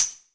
9TAMBOURIN19.wav